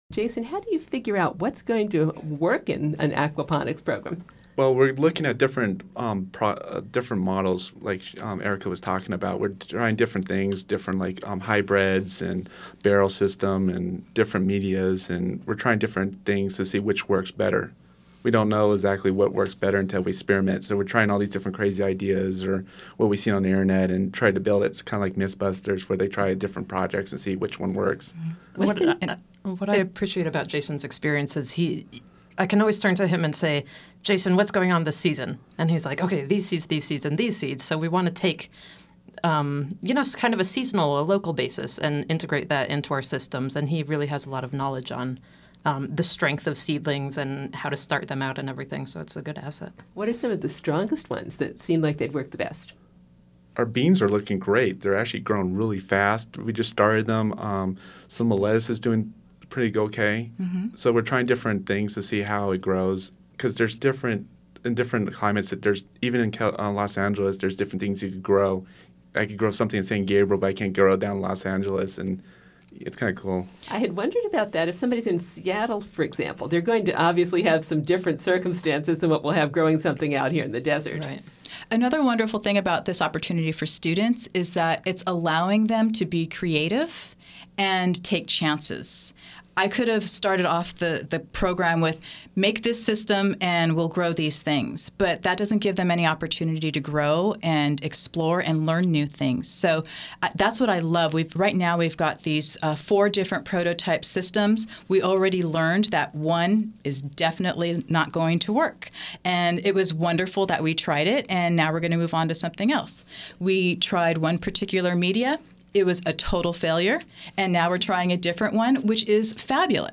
Aquaponics Interview, Part Three